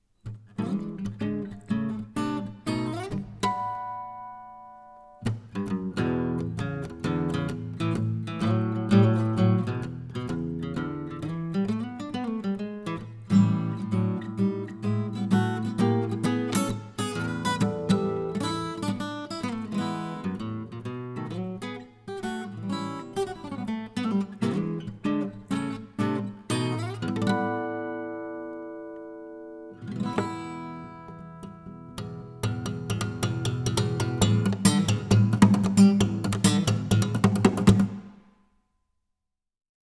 EDIROL R-09
r-09_guitar.wav